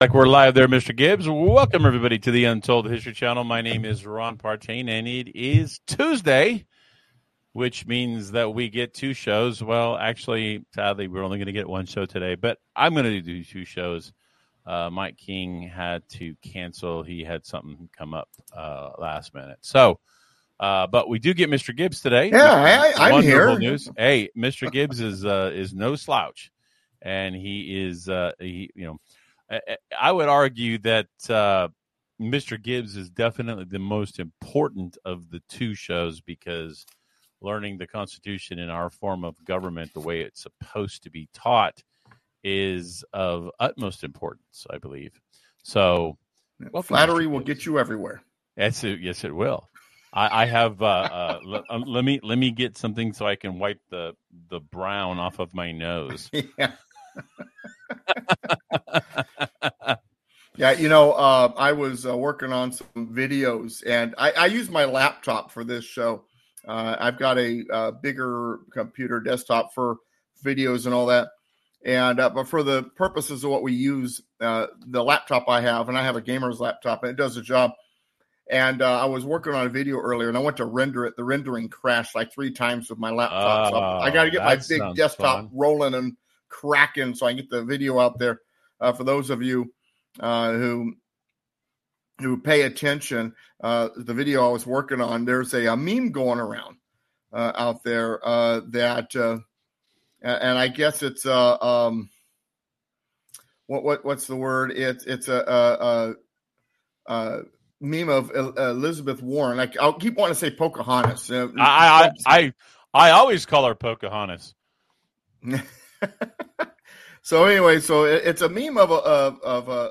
learning-the-constitution-lesson-22-article-ii-executive-branch-continued.mp3